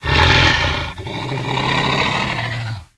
pdog_death_1.ogg